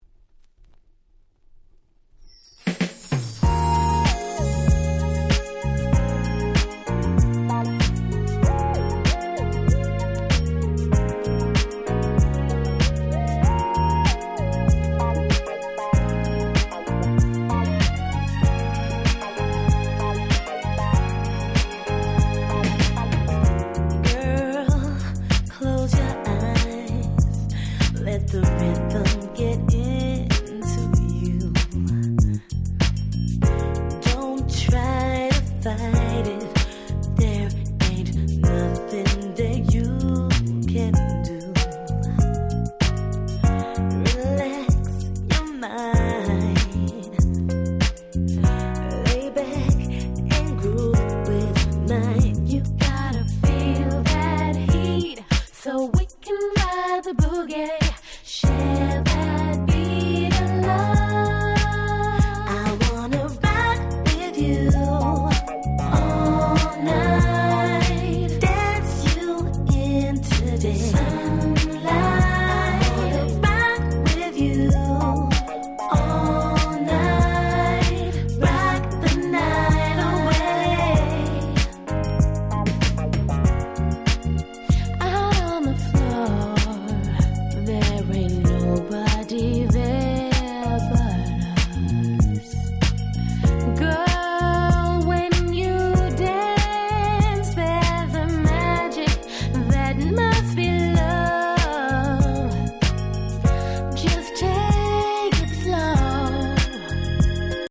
Nice Cover R&B !!